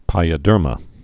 (pīə-dûrmə)